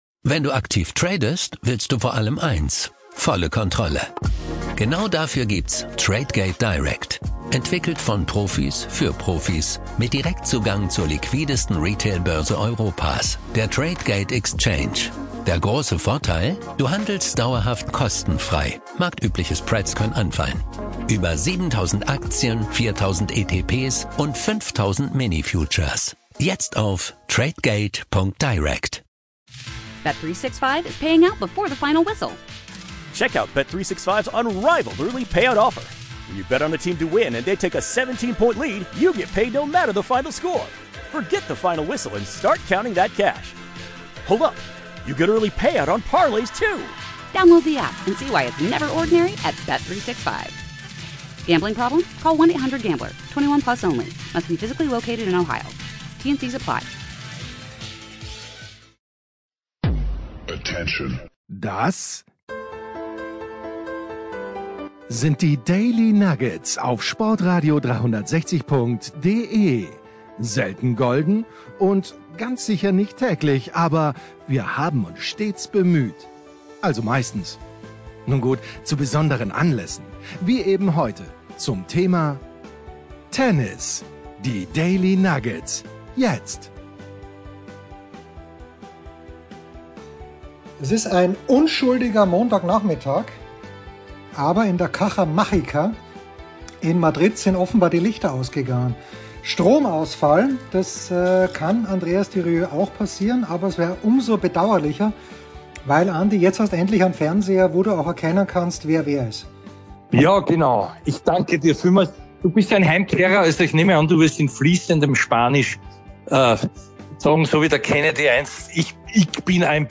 Fünf Tage vor Ort in Madrid: